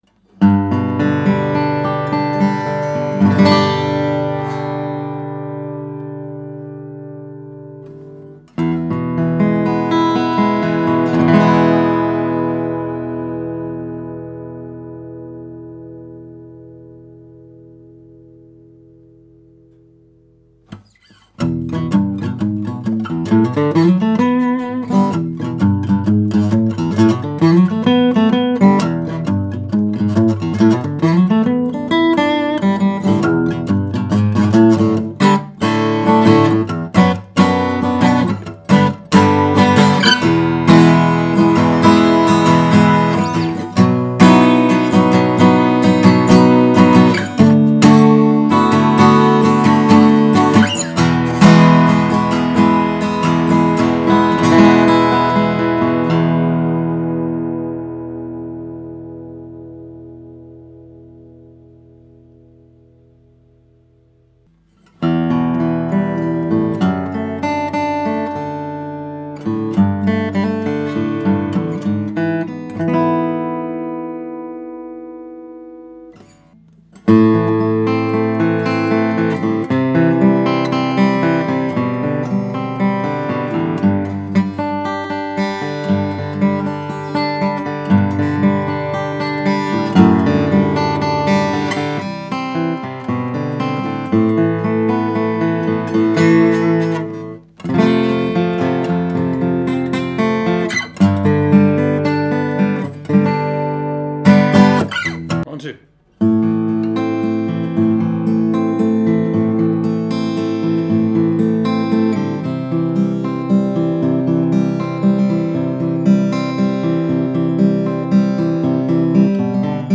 Balanced and open with equal volume and response
The Santa Cruz D Model has a unique tapered bracing, voiced to enhance the midrange and treble without compromising the bass volume.
The result is a more balanced and open dreadnought guitar with equal volume and response across the strings.
Soundimpression-Santa-Cruz-D-Custom.mp3